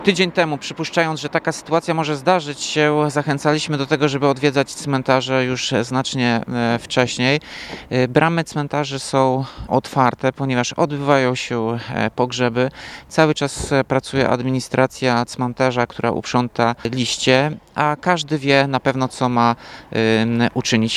– To dlatego, że zaplanowane są uroczystości pogrzebowe, pracuje też obsługa nekropolii – tłumaczy Tomasz Andrukiewicz, prezydent miasta.